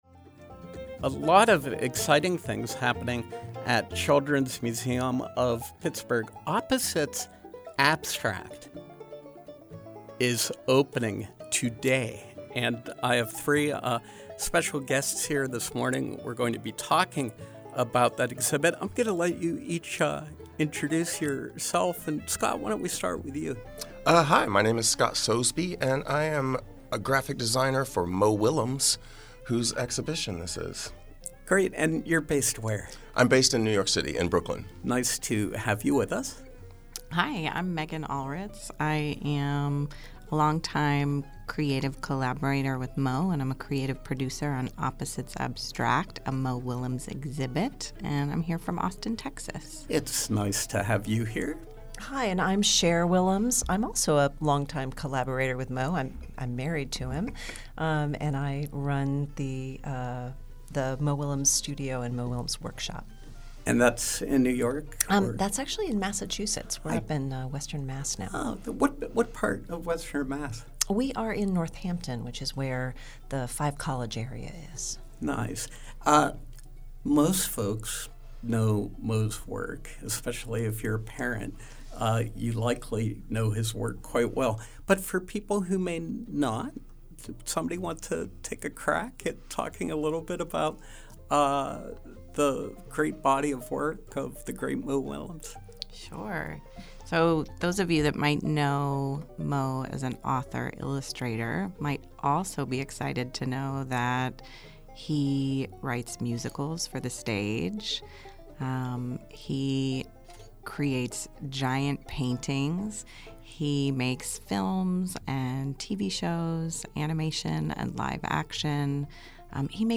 In Studio Pop-Up: Opposites Abstract